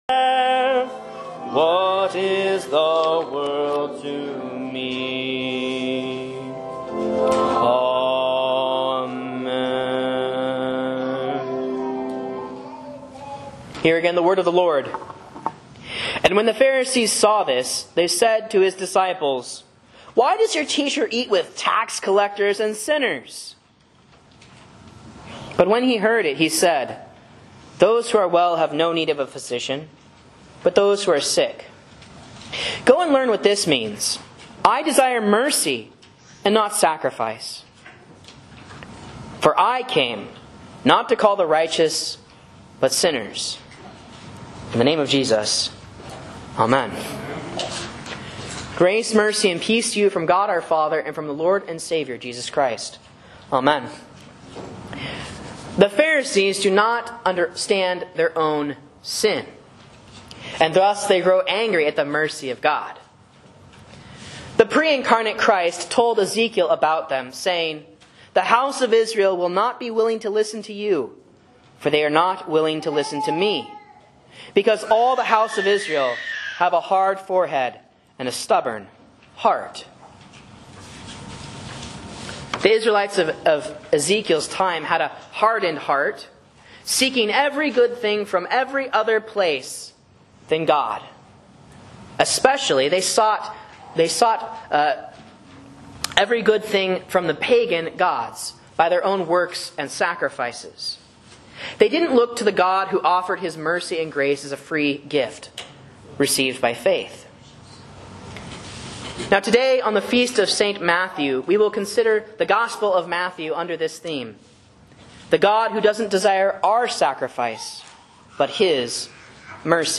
Sermons and Lessons from Faith Lutheran Church, Rogue River, OR
A Sermon on Matthew 9:9-13 for St. Matthew's Day 2025